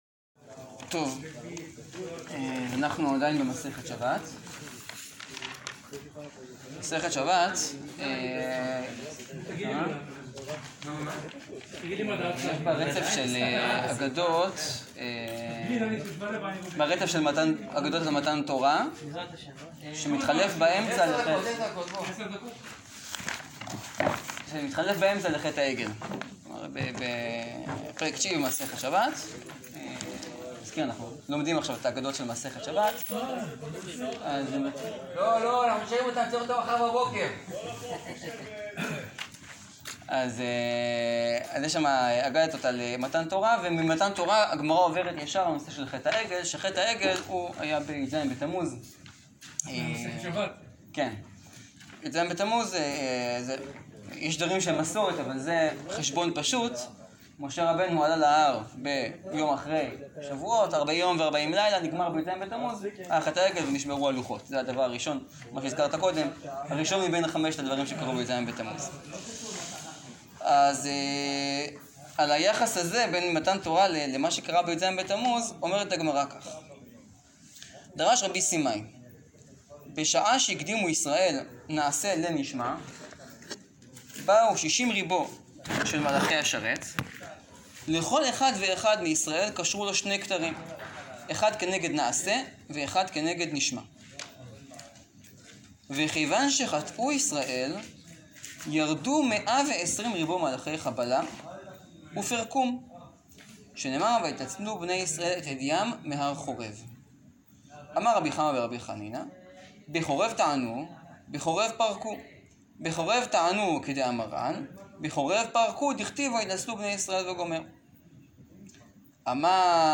שיעור קצר באגדה לי”ז בתמוז – מה איבדנו בחטא העגל, והקשר לכלליות ואינדיווידואליות.